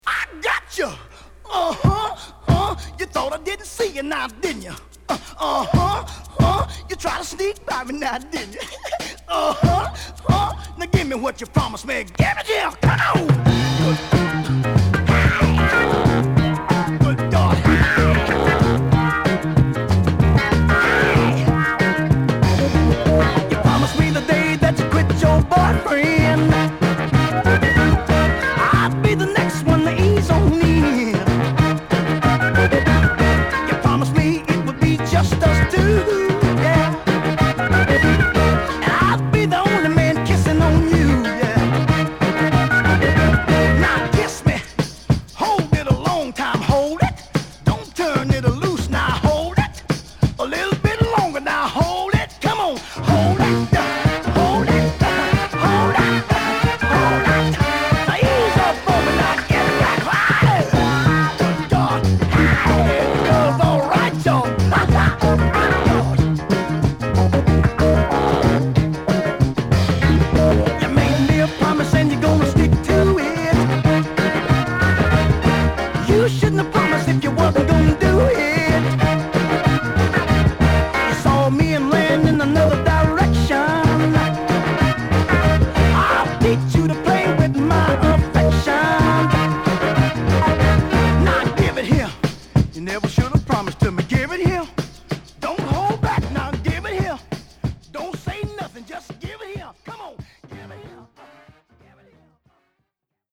ブレイク感マンテンのイントロからファンキーなホーンや土臭くも太いベース、オヤジ臭さも漂うヴォーカル等が絡む名曲！